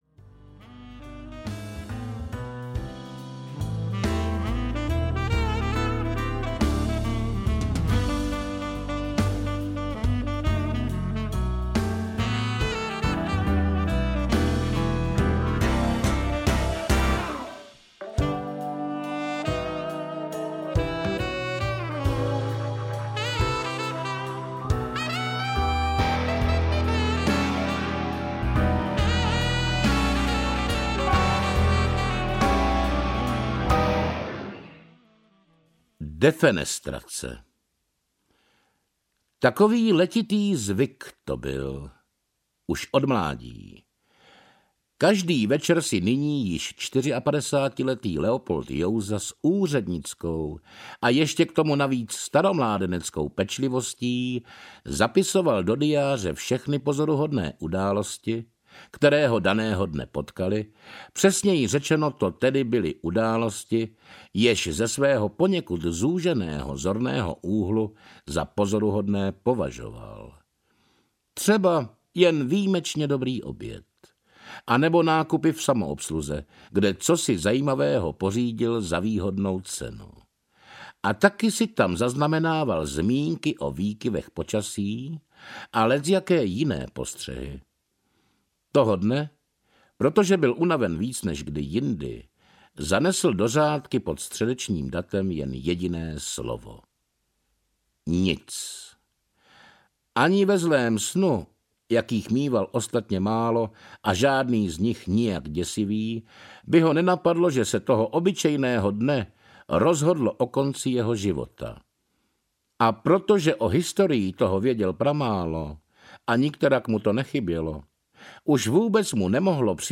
Doteky osudu audiokniha
Ukázka z knihy
Třetí díl úspěšné kompilace kriminálních případů z policejních archivů navazuje na předchozí Doteky hříchu a Doteky bezmoci. Přináší opět devět samostatných detektivních povídek v podání devíti skvělých interpretů.